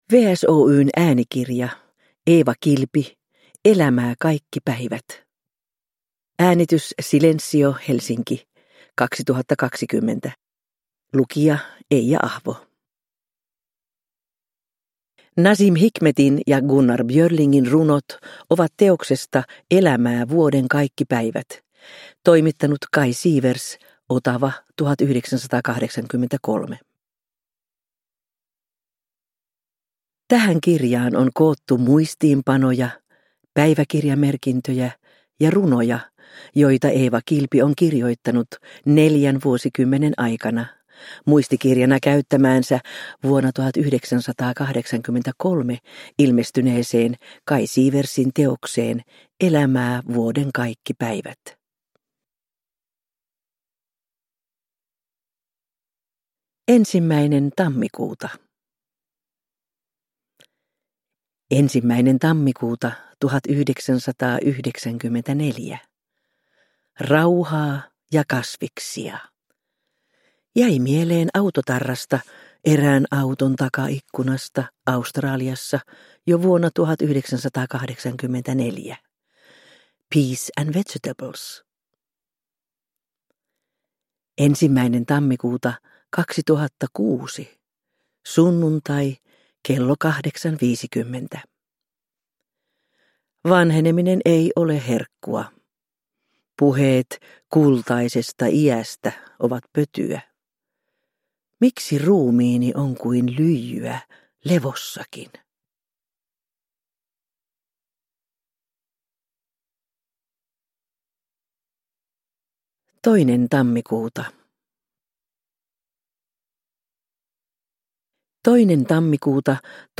Elämää kaikki päivät – Ljudbok – Laddas ner
Uppläsare: Eija Ahvo